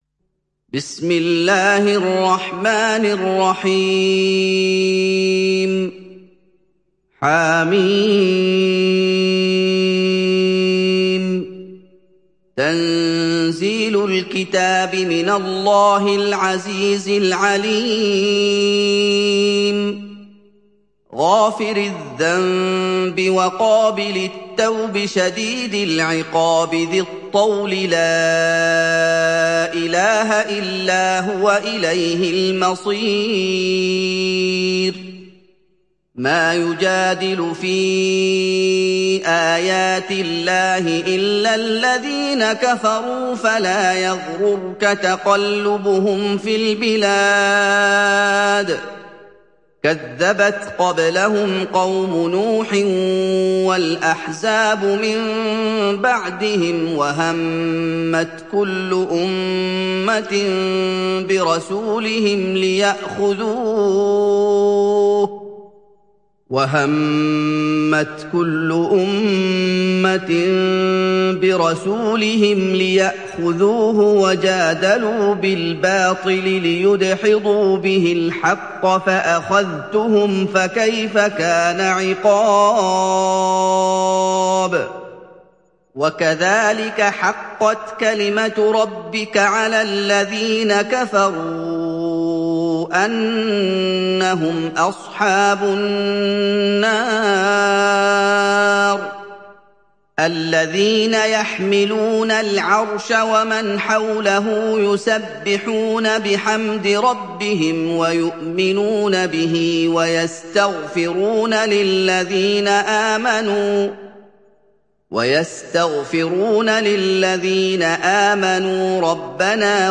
Sourate Ghafir Télécharger mp3 Muhammad Ayoub Riwayat Hafs an Assim, Téléchargez le Coran et écoutez les liens directs complets mp3